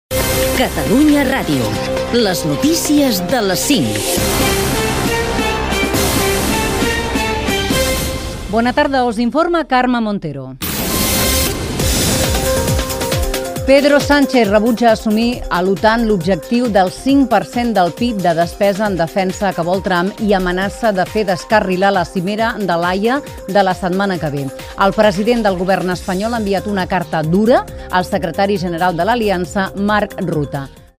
Butlletí informatiu: Pedro Sanchez-OTAN - Catalunya Ràdio, 2025